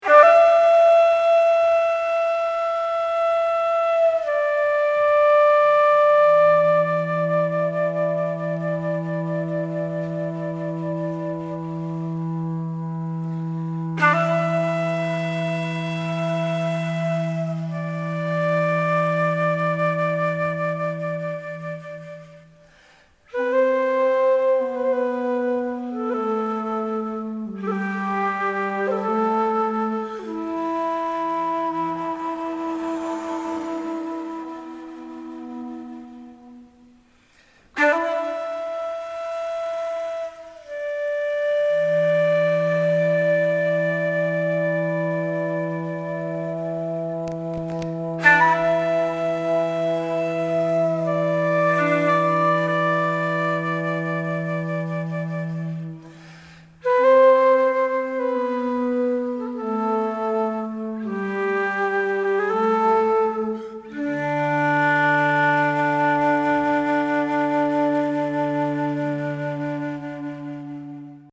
flûtes traversières